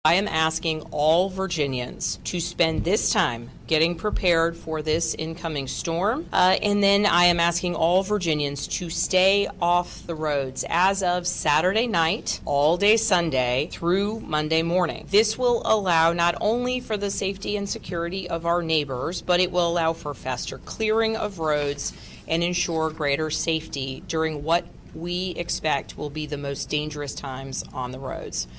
During a press conference this afternoon, Governor Abigail Spanberger declared a State of Emergency ahead of winter weather expected to hit the Commonwealth this weekend. Spanberger asked all Virginians to air on the side of caution: